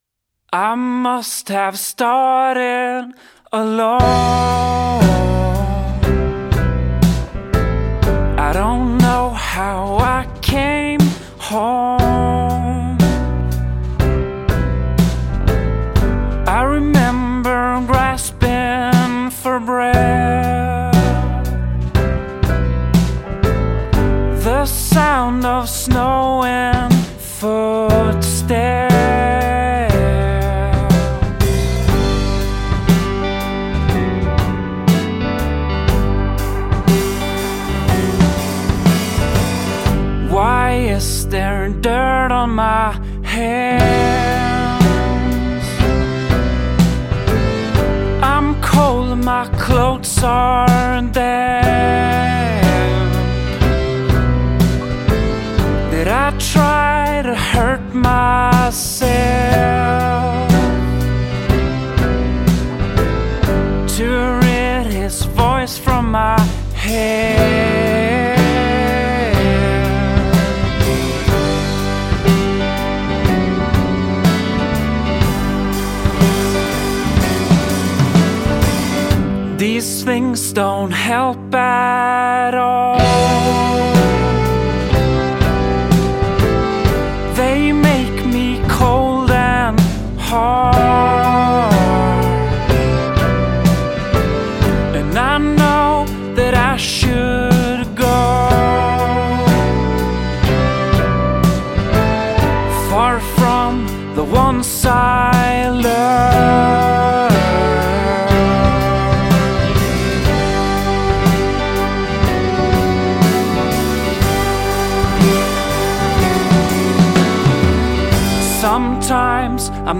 band folk rock proveniente da Vänersborg